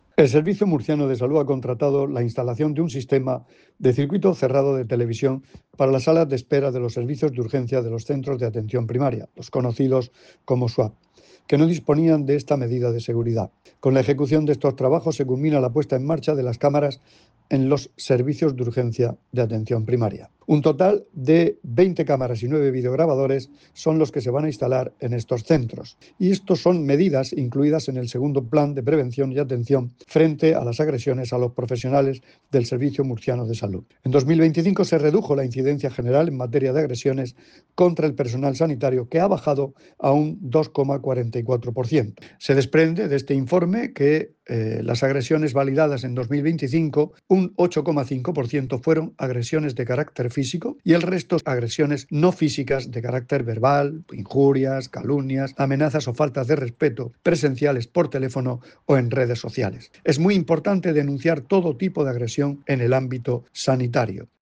Sonido/ Declaraciones del consejero de Salud, Juan José Pedreño, sobre el sistema de circuito cerrado de televisión para las salas de espera de los Servicios de Urgencias de los Centros de Atención Primaria.